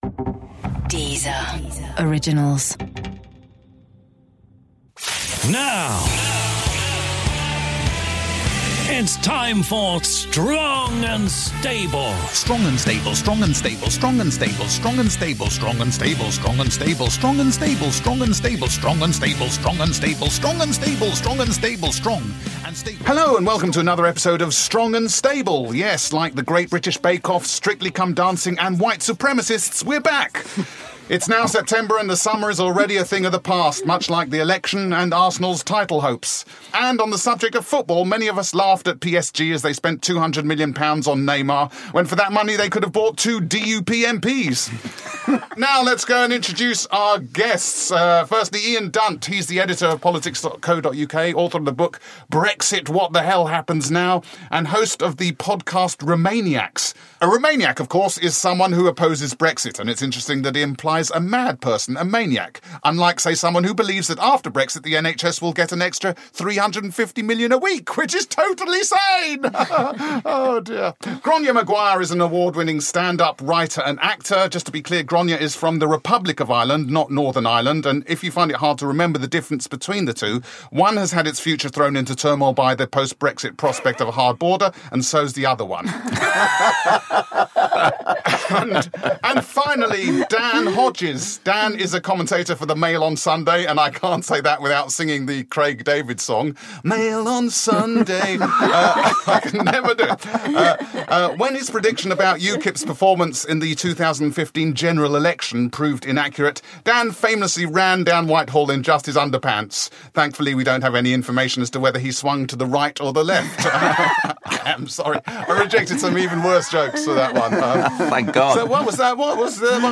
This week our host David Schneider is joined in the studio by Ian Dunt, Grainne Maguire and Dan Hodges to pick over the latest political goings-on. Plus, we hear from roving reporter Jonathan Pie on 'the end of austerity'...